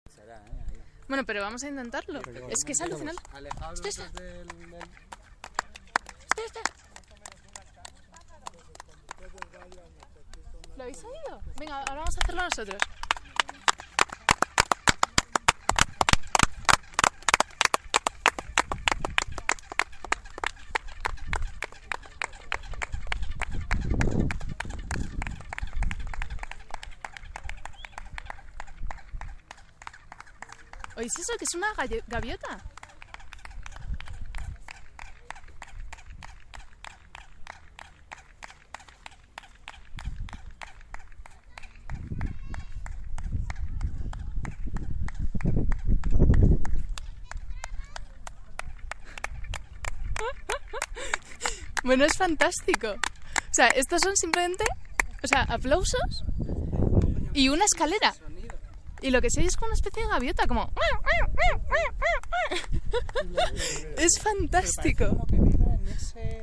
Porque es cierto que, cuando se dan palmadas frente a una pirámide, como puede escucharse en el vídeo que encabeza la entrada, esta devuelve una especie de graznido similar al de algunos pájaros.
Mayor separación, mayor periodo, menor frecuencia: implica un sonido descendente, que es exactamente lo que sucede.
cholula.ogg